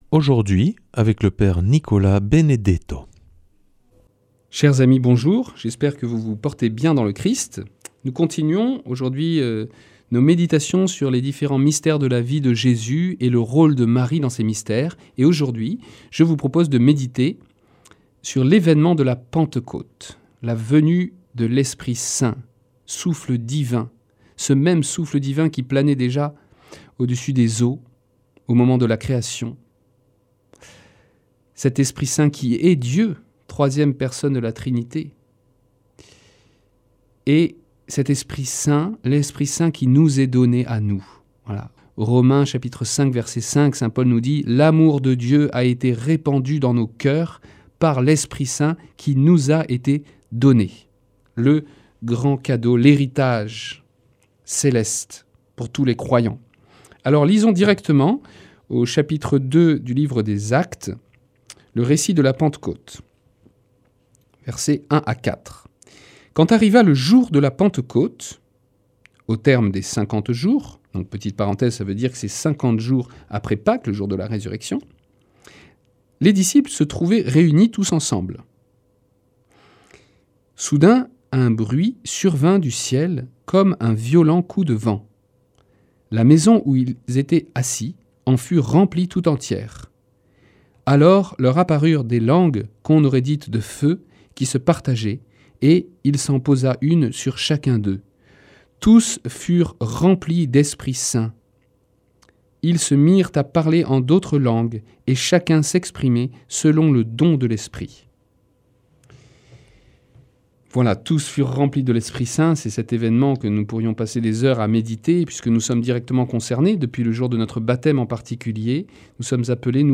vendredi 14 février 2025 Enseignement Marial Durée 10 min